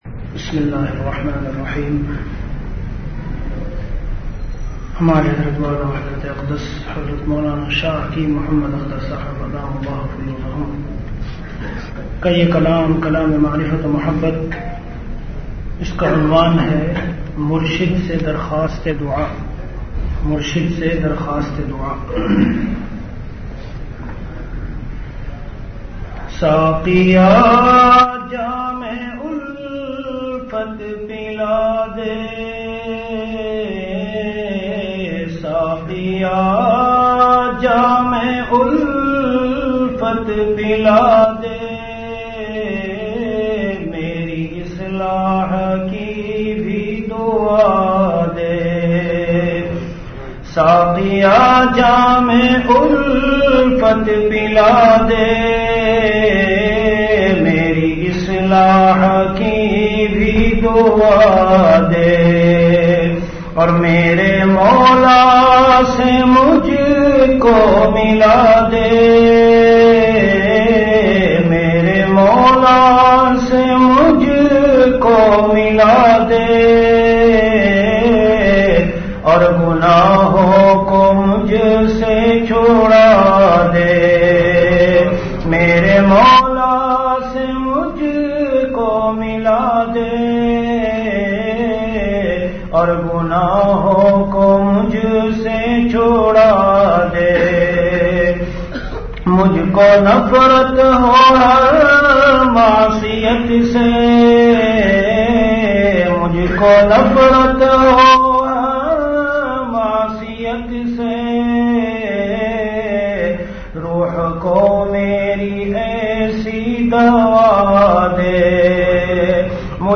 Bayanat · Khanqah Imdadia Ashrafia